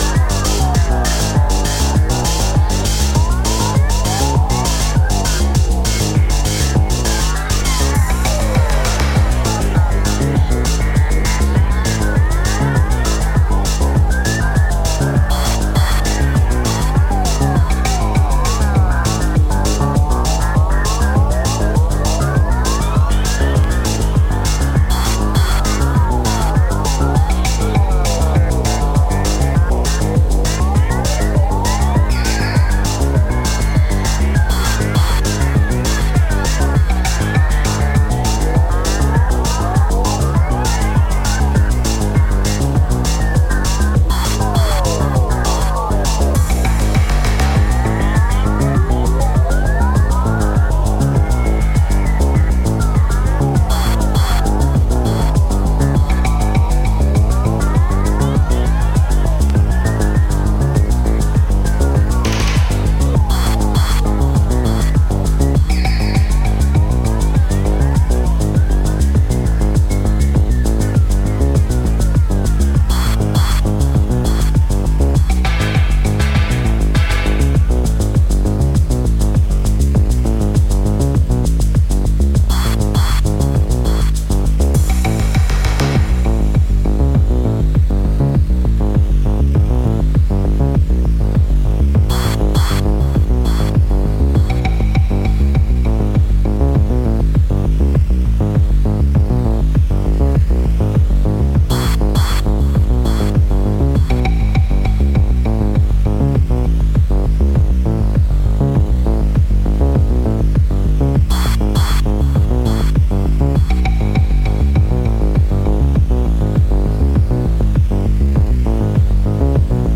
ヘヴィなベースループをなぞり、サイケデリックなパッドが旋回する100BPM